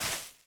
Minecraft Version Minecraft Version 1.21.5 Latest Release | Latest Snapshot 1.21.5 / assets / minecraft / sounds / block / suspicious_sand / place4.ogg Compare With Compare With Latest Release | Latest Snapshot